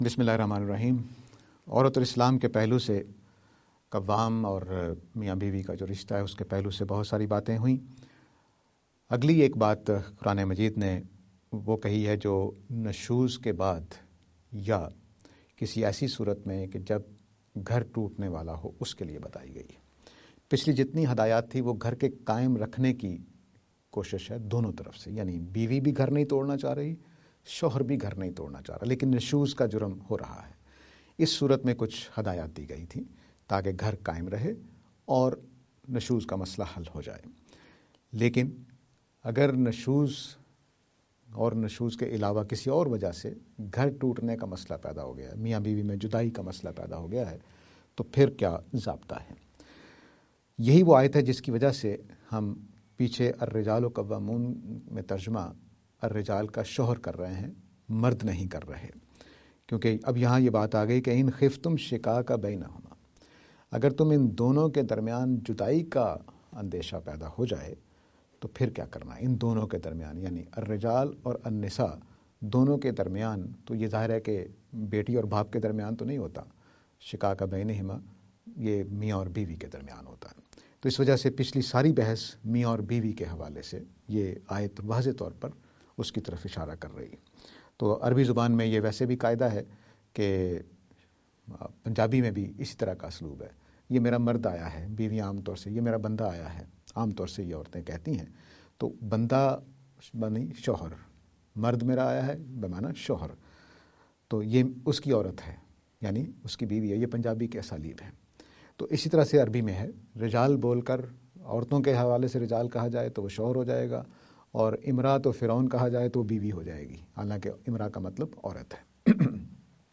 lecture series on "Women and Islam".